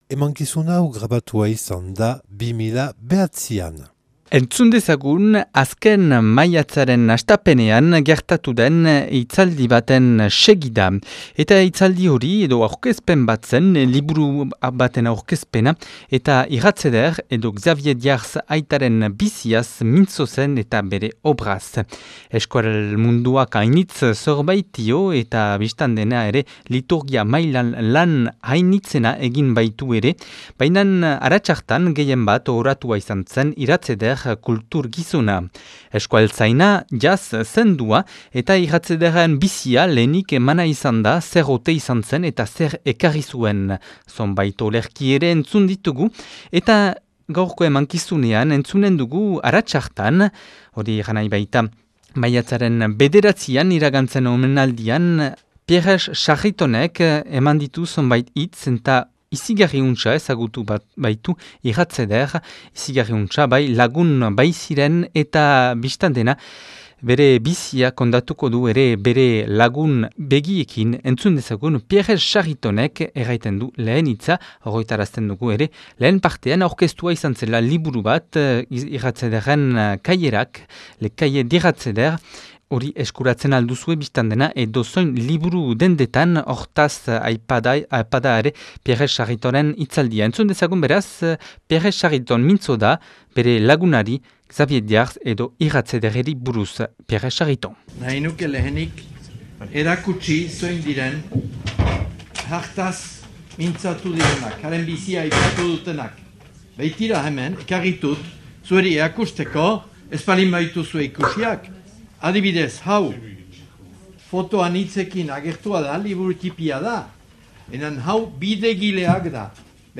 Donibane Lohitzunen 2009 urtean grabatua.